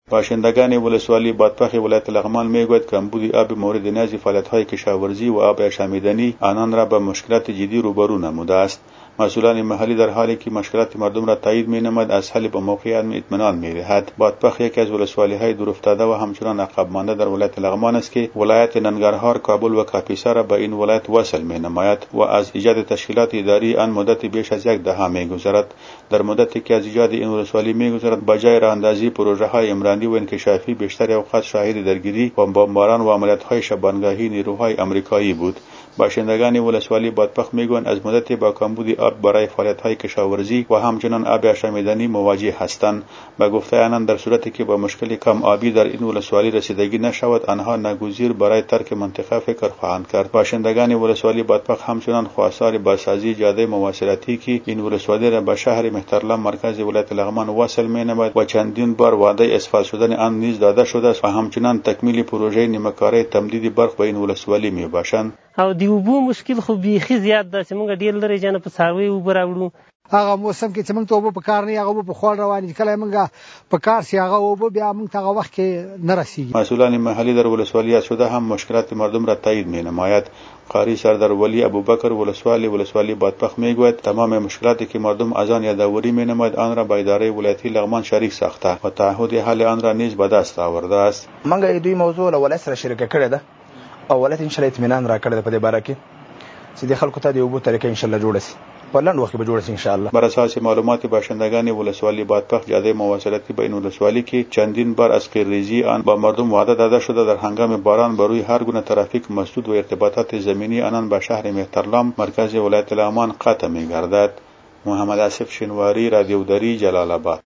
گزارش